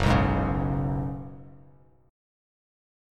F#sus2sus4 chord